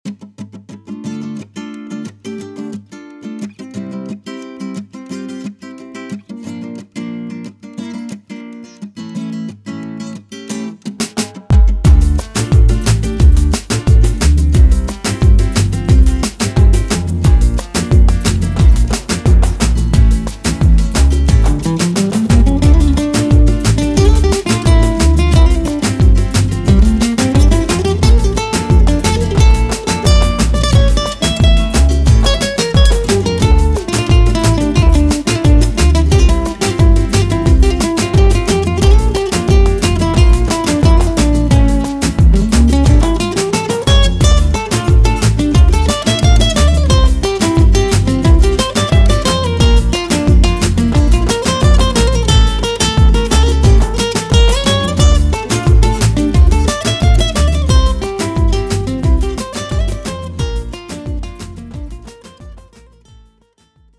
Style 1: Latin Jam
Let's use this progression in its native genre: latin, or flamenco style.
For example, Dm and C will both be seen alongside their sus4 counterparts, and Bb will sometimes have a #11 added to it.
latin-andalusian.mp3